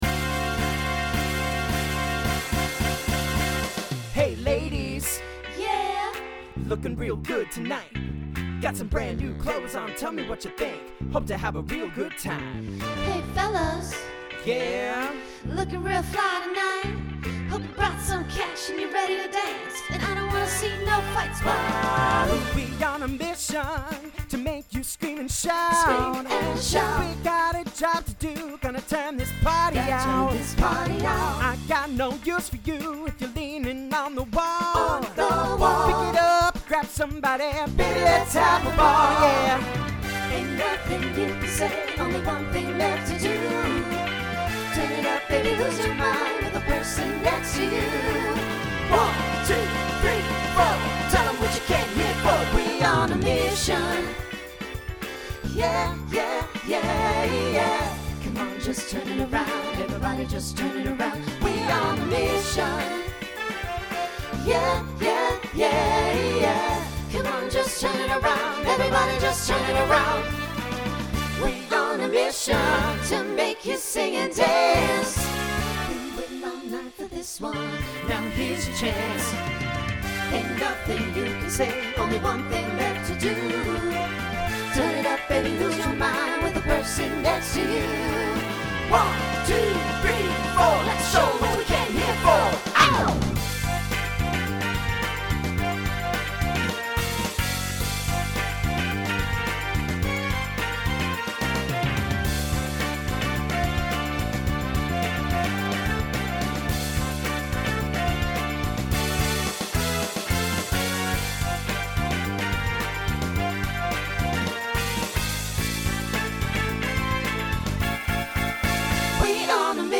Pop/Dance , Rock Instrumental combo
Mid-tempo , Opener Voicing SATB